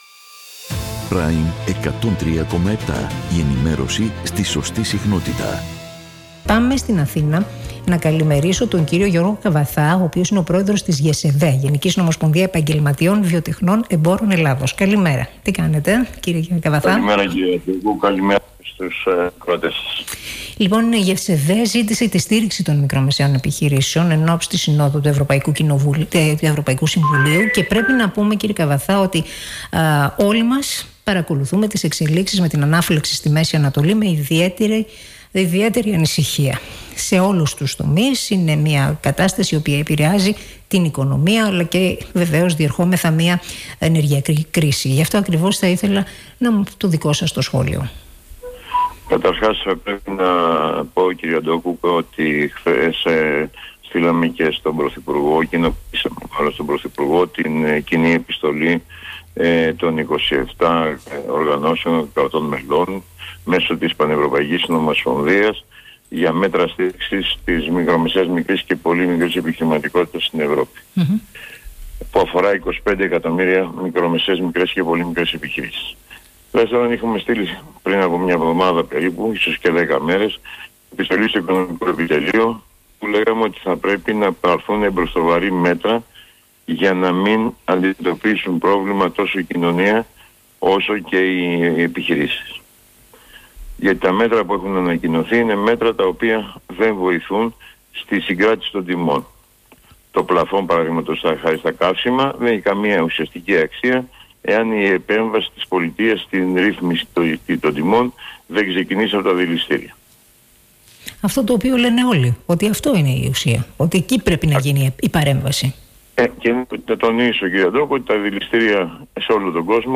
Με δηλώσεις του στον Ρ/Σ prime 103,7